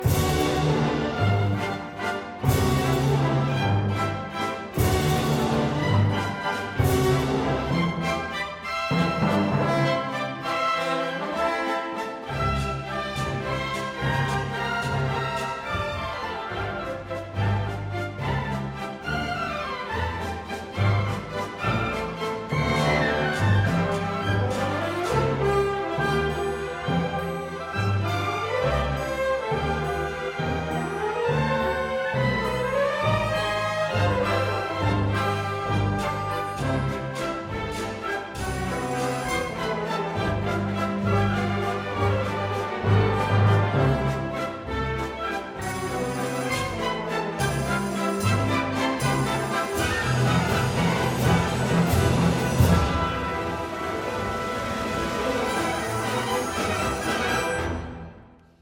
Strauss : Le Chevalier à la rose, suite
Enfin, retour en Allemagne avec la richesse orchestrale de la Suite qui résume tout l’opéra de Richard Strauss, Le Chevalier à la rose : une romance explorant les thèmes de la jeunesse et du désir.